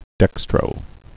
(dĕkstrō)